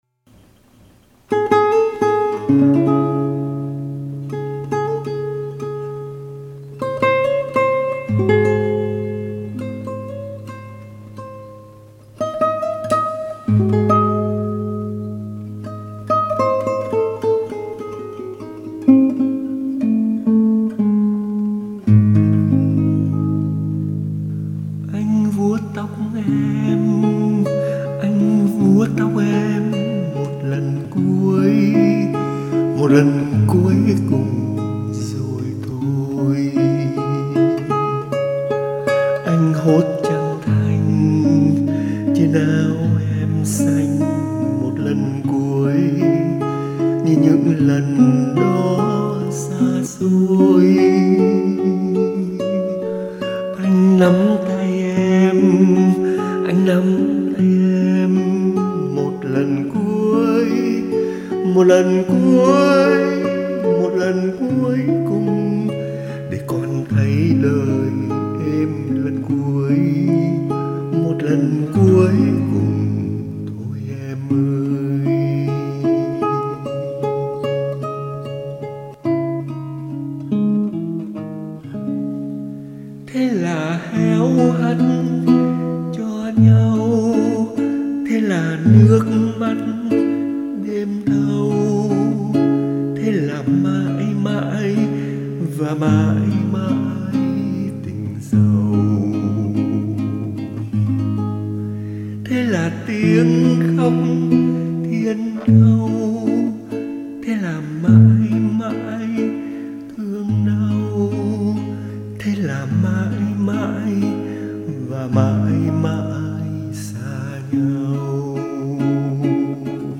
Tác giả ghi “Rất chậm, Tuyệt vọng”.